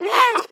Звук мяукающей пантеры